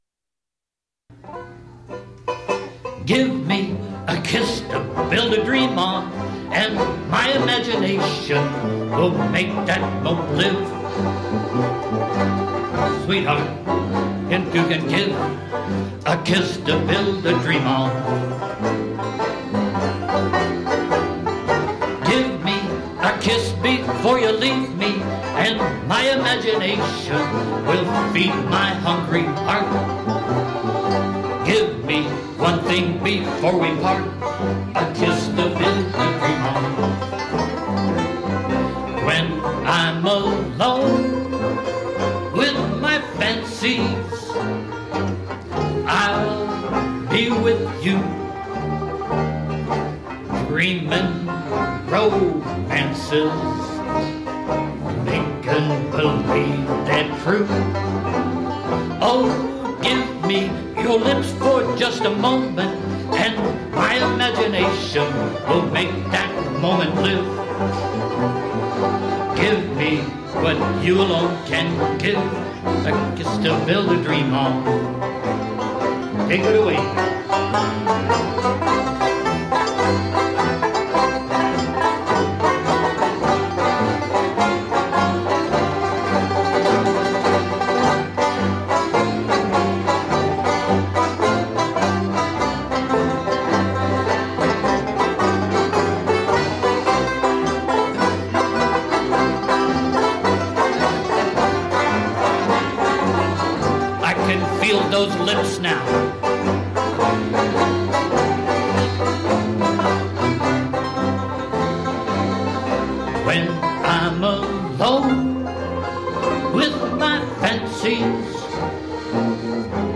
8-beat intro.
Vocal